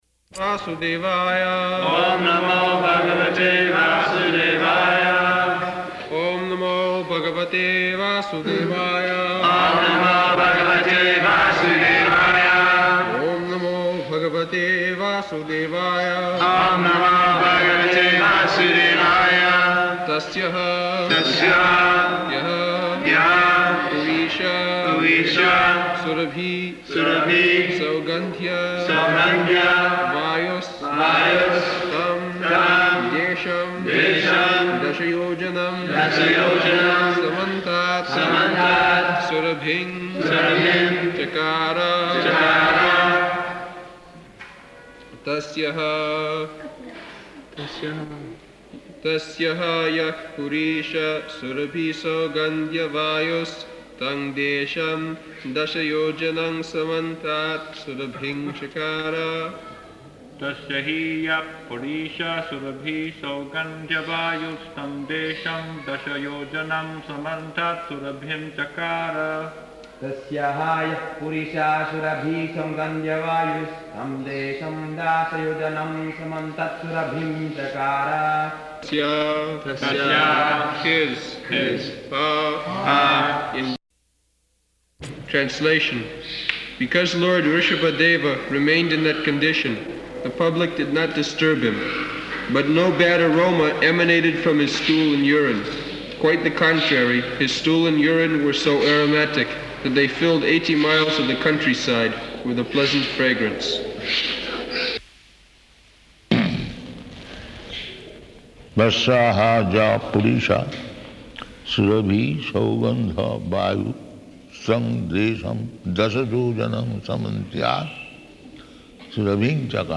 November 20th 1976 Location: Vṛndāvana Audio file
[devotees repeat] [leads chanting of verse, etc.]